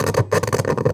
radio_tv_electronic_static_04.wav